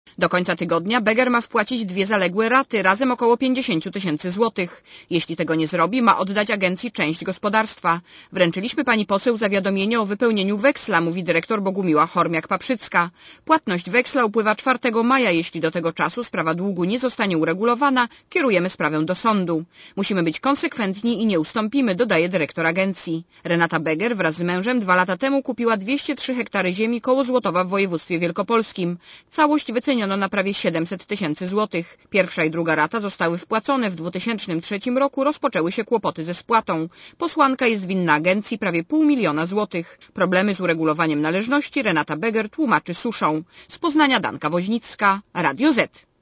Posłuchaj relacji reporterki Radia ZET